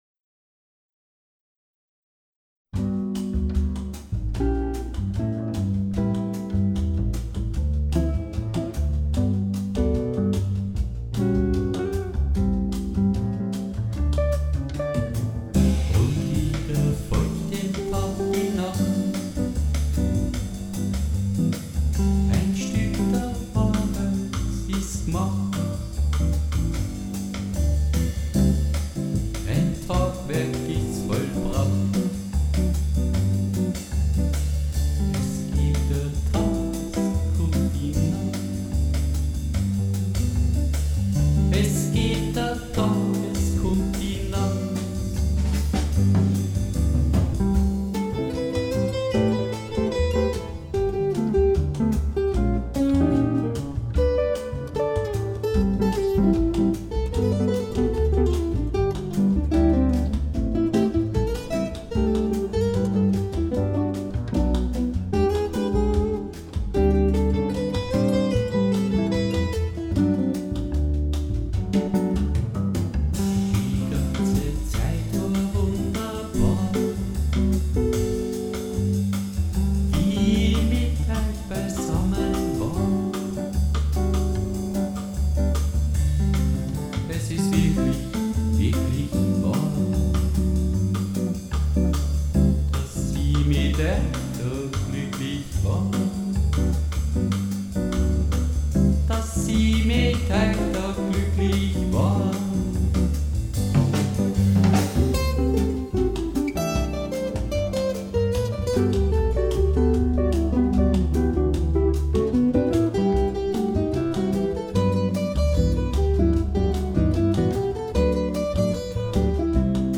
3 Bossanova 2019 Nachdem ich 2015 endlich richtigen Gitarreunterricht genommen hatte, war es endlich möglich das zu verwirklichen, was ich musikalisch immer wollte. Ich spielte Rhythmusgitarre, Bass und Solo in den Computer ein, mischte mit dem Midi Schlagzeugpatterns dazu, Gesang draufgelegt und fertig war's. Rhythmus: Konzertgitarre mit Bossanovaphrasen Solo: Brazil mit der E-Gitarre und Clean Verstärker Bass: Akkoustik Bass Gitarre übers Midi verfremdet Drums: Einfache BossaDrums auf Midipads gelegt